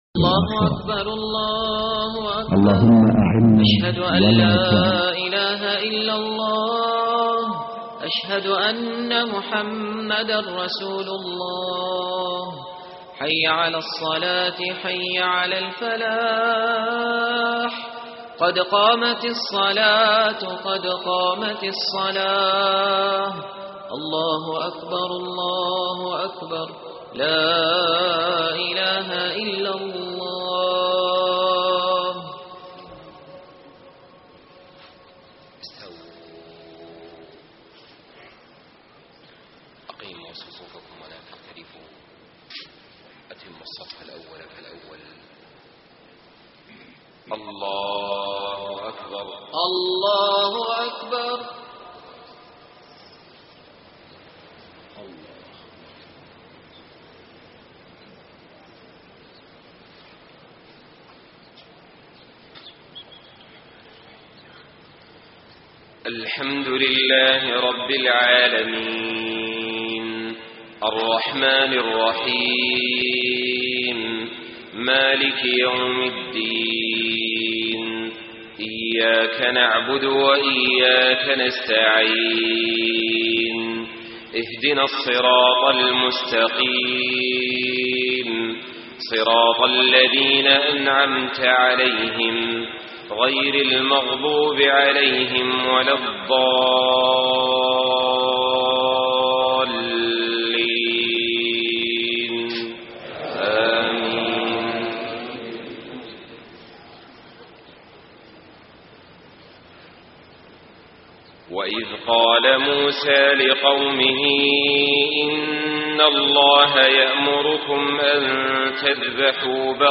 صلاة الفجر 2-7-1434 هـ من سورة البقرة > 1434 🕋 > الفروض - تلاوات الحرمين